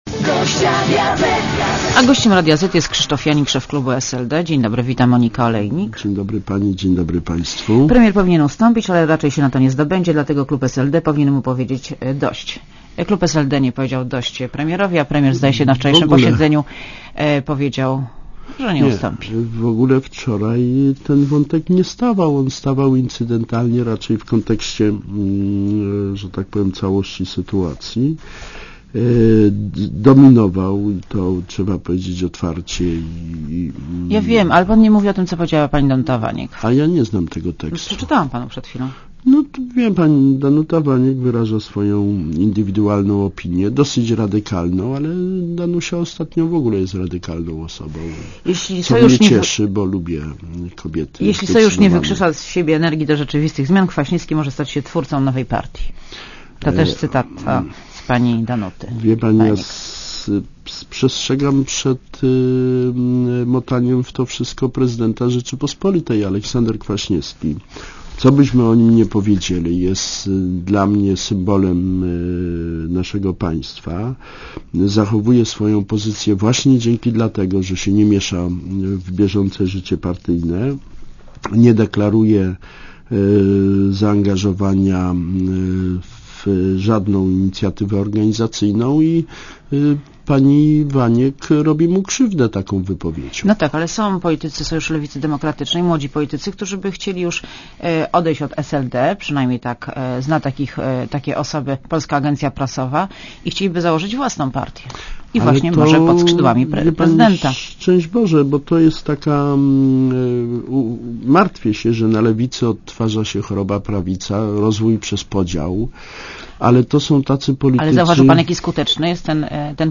Posłuchaj wywiadu (2,29MB) Gościem Radia Zet jest Krzysztof Janik, szef klubu SLD.